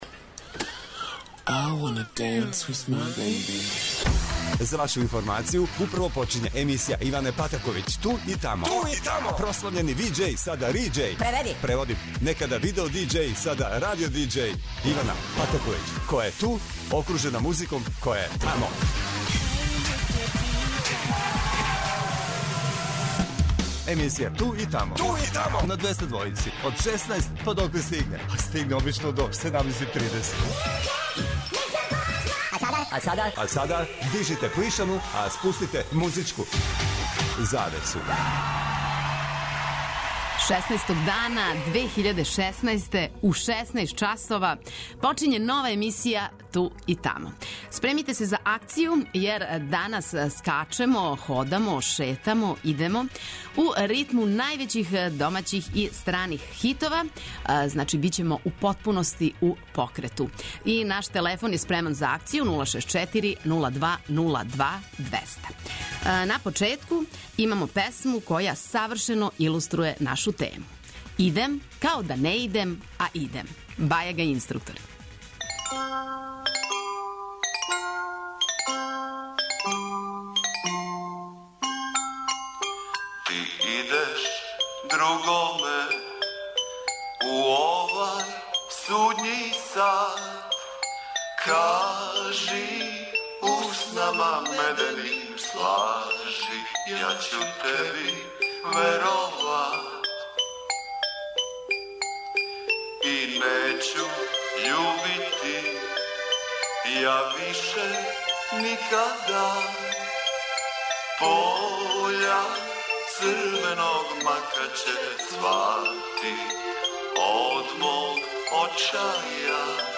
Ново издање емисије Ту и тамо биће у покрету. У ритму великих домаћих и страних хитова слушаоци Двестадвојке ће шетати, ходати, скакати, померати се горе доле.